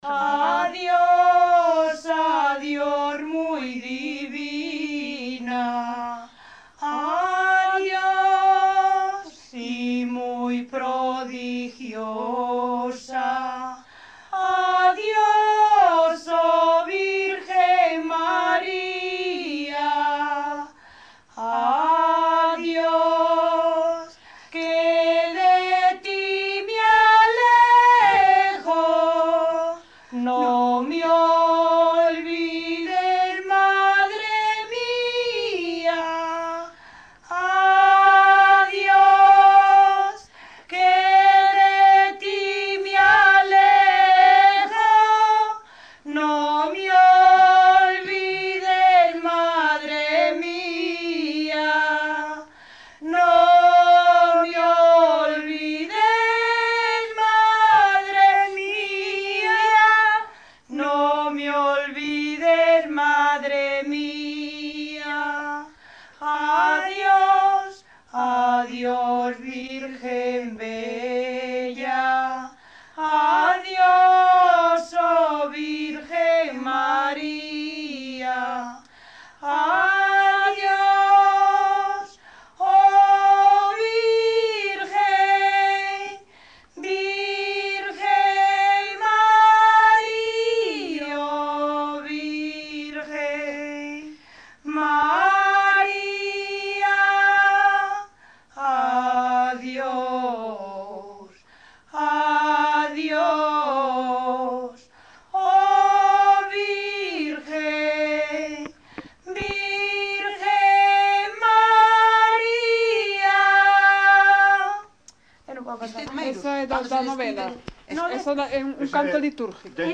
Canto litúrxico
Concello: Neves, As.
Tipo de rexistro: Musical
Soporte orixinal: Casete
Datos musicais Refrán
Instrumentación: Voz
Instrumentos: Voces femininas